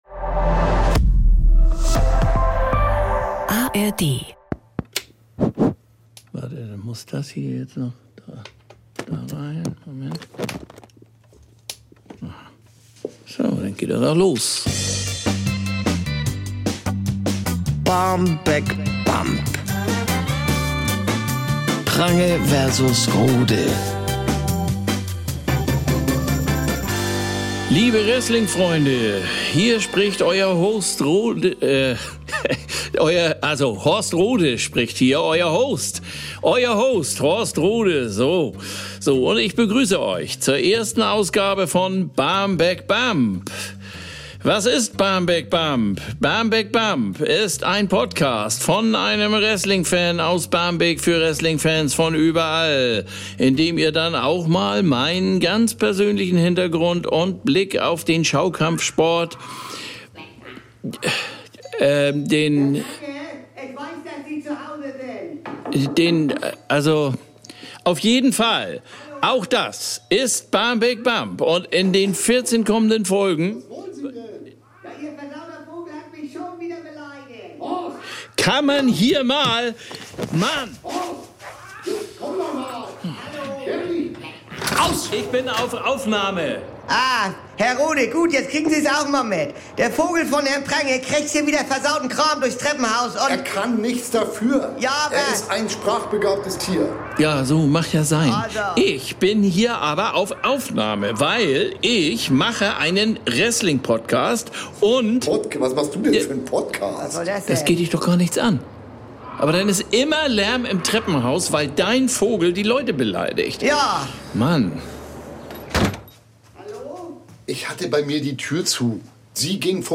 Ralf Prange: Bjarne Mädel Horst Rohde: Olli Dittrich Sprecherin: Doris Kunstmann